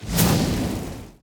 Free Fantasy SFX Pack By TomMusic / OGG Files / SFX / Spells / Fireball 1.ogg
Fireball 1.ogg